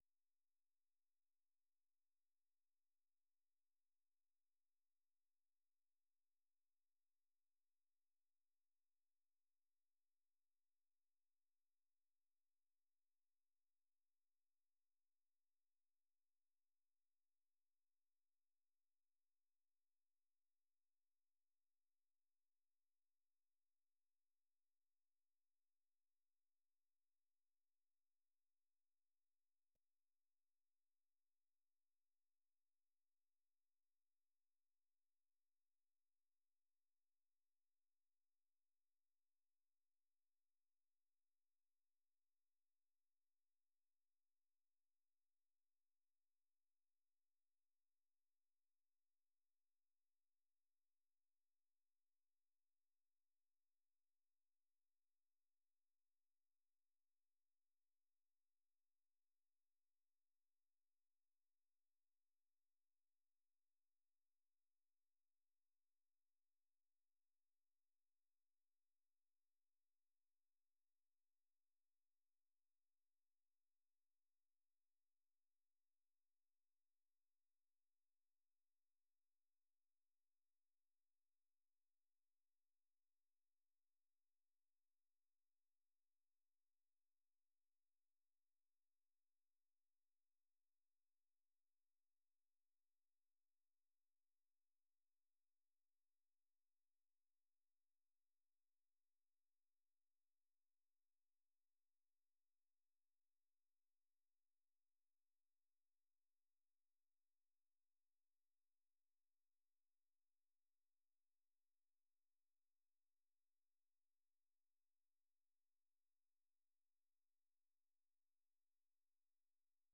ဗွီအိုအေမြန်မာပိုင်းရဲ့ ညပိုင်း မြန်မာစံတော်ချိန် ၉ နာရီမှ ၁၀နာရီအထိ ရေဒီယိုအစီအစဉ်ကို ရေဒီယိုကနေ ထုတ်လွှင့်ချိန်နဲ့ တပြိုင်နက်ထဲမှာပဲ Facebook နဲ့ Youtube ကနေလည်း တိုက်ရိုက် ထုတ်လွှင့်ပေးနေပါတယ်။